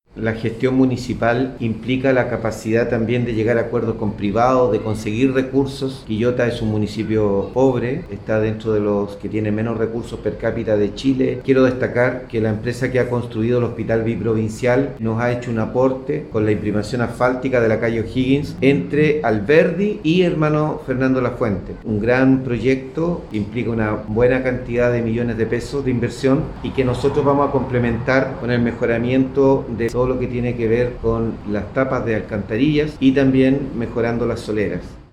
01-ALCALDE-Gestion-y-recapado-de-OHiggins.mp3